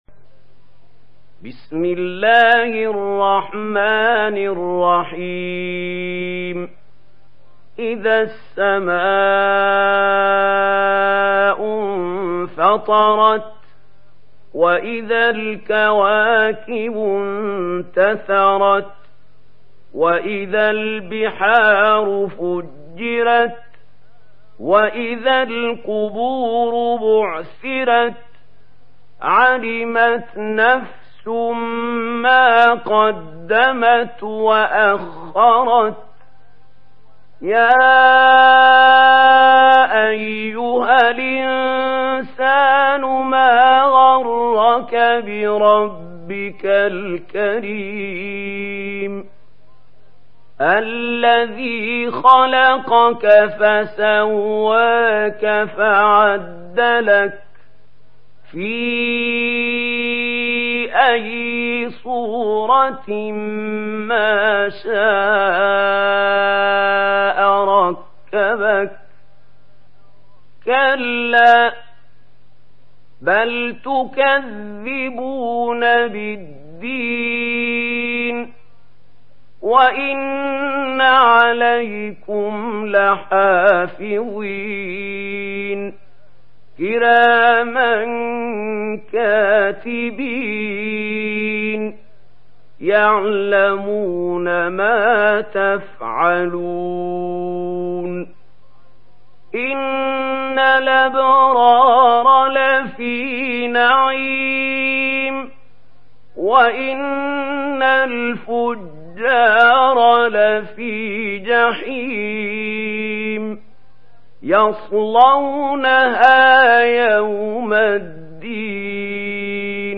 Surah আল-ইনফিতার MP3 in the Voice of Mahmoud Khalil Al-Hussary in Warsh Narration
Murattal Warsh An Nafi